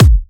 Kicks
pcp_kick16.wav